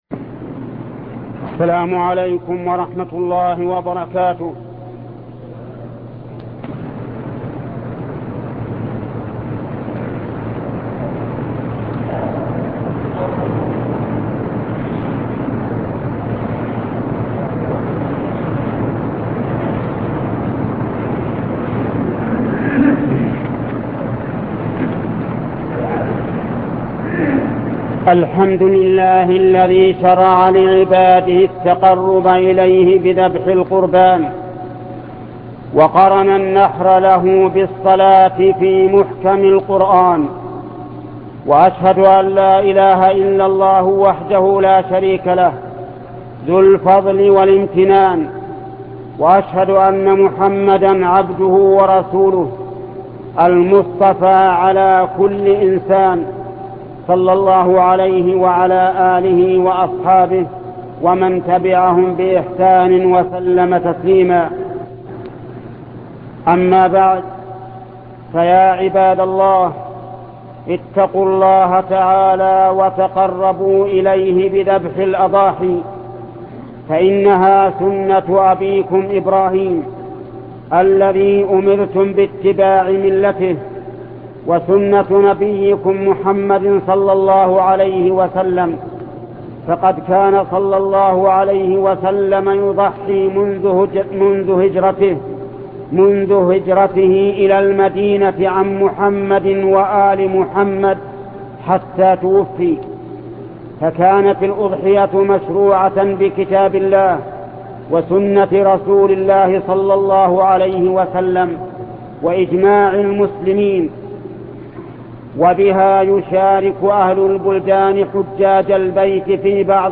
خطبة أحكام الأضحية - الحث على الإكثار من الذكر و متى يبدأ ومتى ينتهي و الخروج إلى صلاة العيد الشيخ محمد بن صالح العثيمين